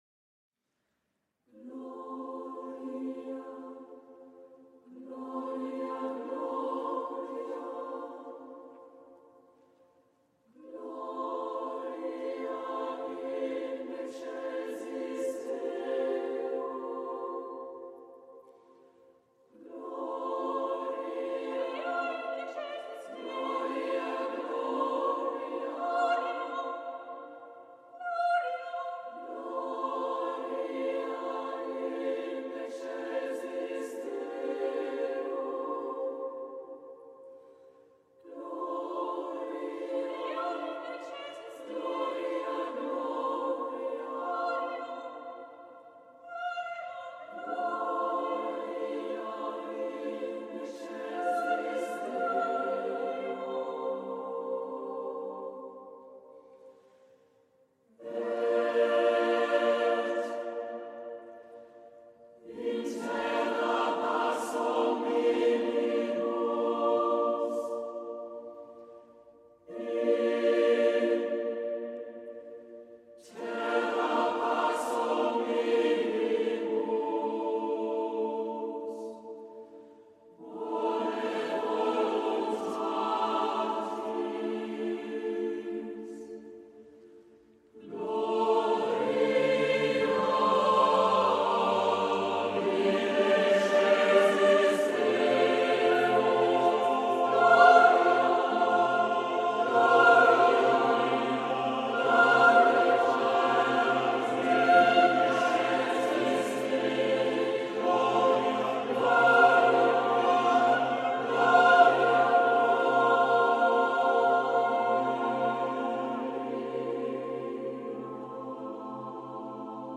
Voicing: SSAATTBB a cappella